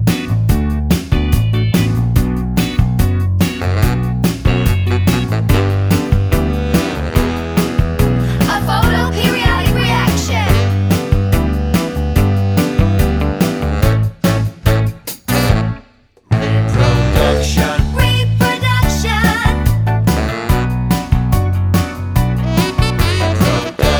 No Backing Vocals Soundtracks 4:09 Buy £1.50